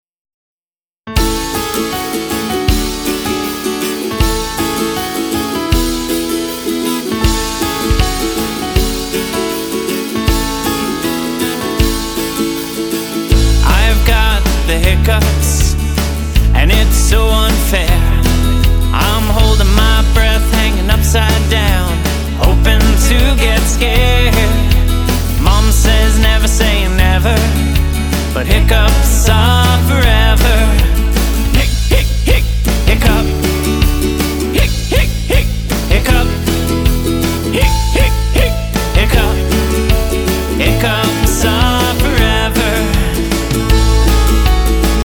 A silly song
Listen to a sample of this song.